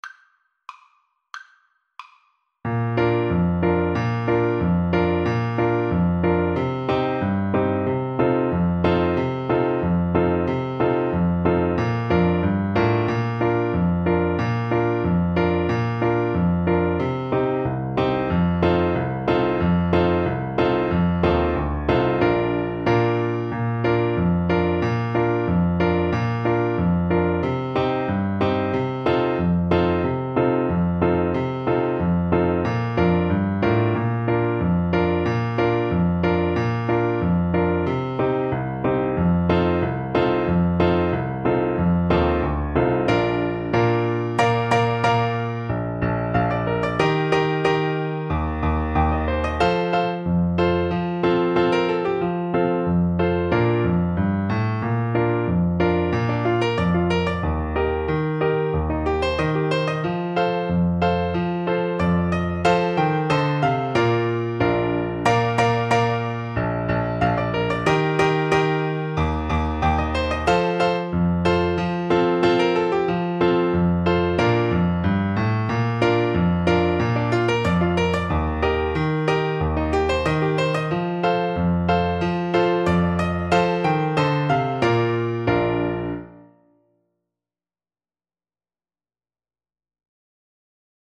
Traditional Music of unknown author.
2/4 (View more 2/4 Music)
Moderato =c.92
Bb major (Sounding Pitch) (View more Bb major Music for Trombone )